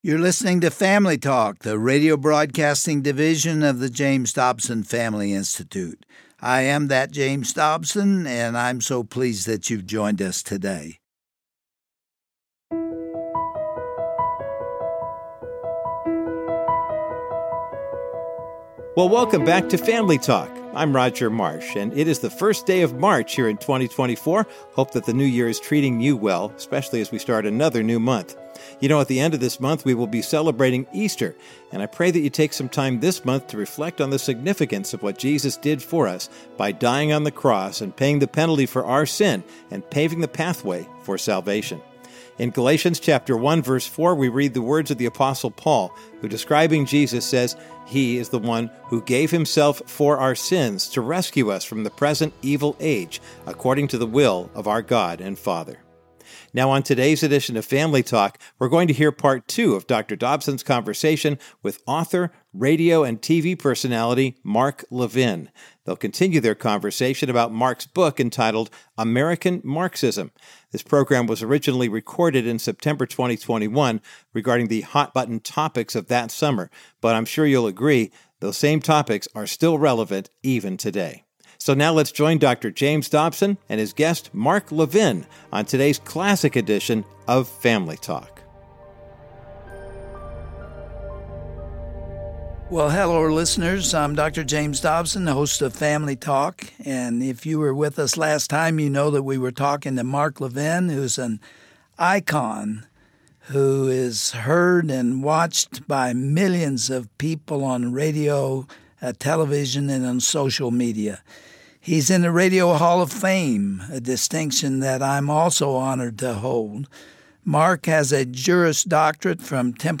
On today's edition of Family Talk, Dr. James Dobson concludes his powerful discussion with Mark Levin, author of American Marxism, as he describes the stark differences between Marxism and our founding fathers' vision for America. Some of these contradictions are repression vs. engagement; compliance vs. speech; conformity vs. independence; and subjugation vs. liberty.